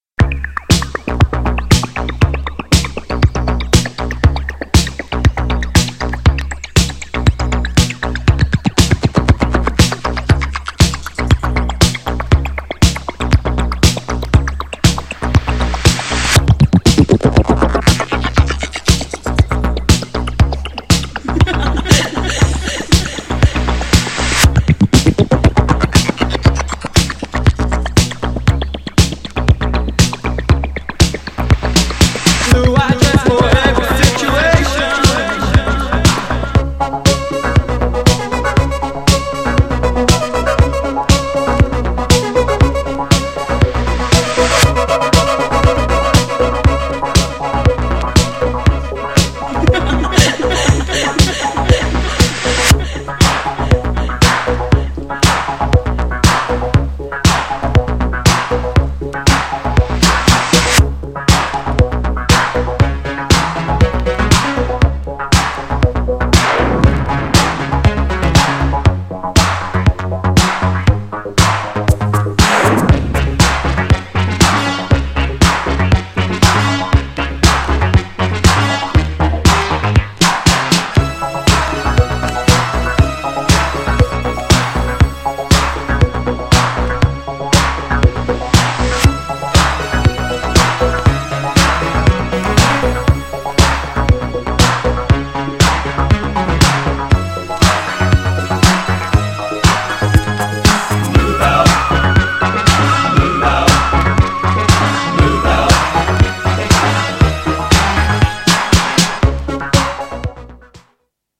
DUBも最高。
GENRE Dance Classic
BPM 116〜120BPM